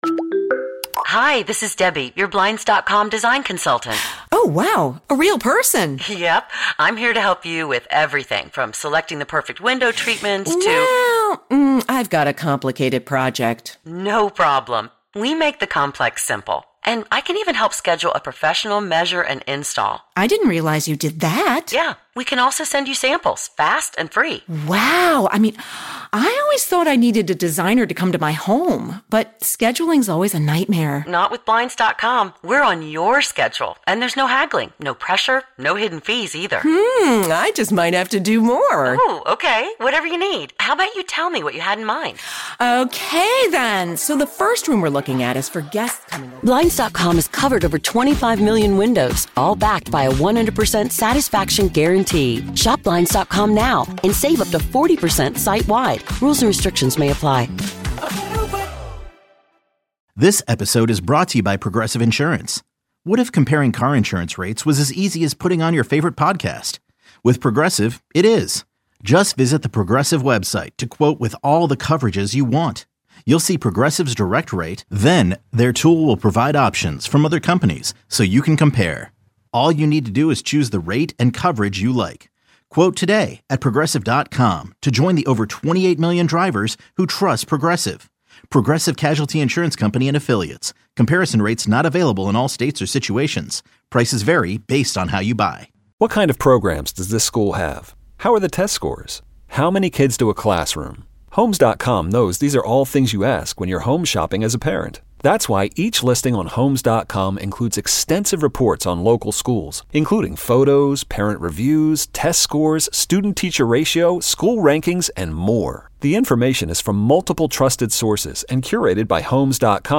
-NUMEROUS callers chime in on what they want the Steelers to do on Thursday night.